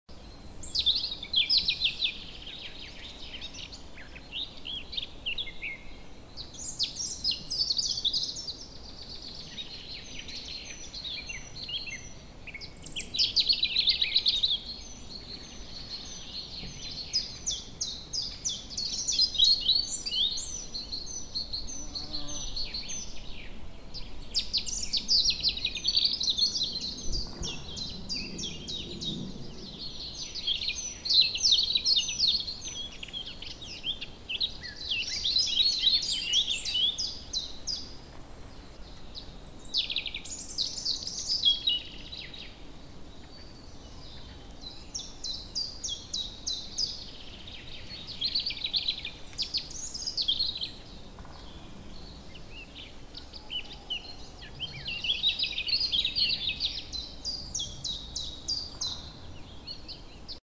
nature-birds.mp3